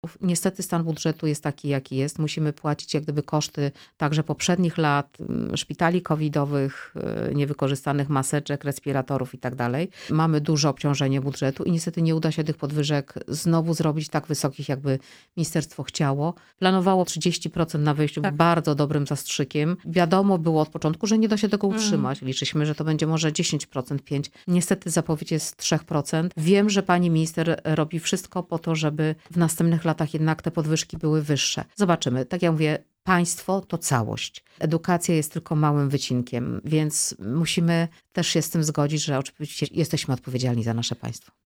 Sprawę komentował „Poranny Gość” – kurator Ewa Skrzywanek.